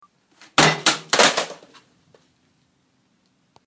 笔记本电脑掉落在地板上 " 笔记本电脑掉落1
描述：滴在水泥和打破的膝上型计算机
Tag: 笔记本电脑砸 笔记本电脑打破 笔记本电脑跌落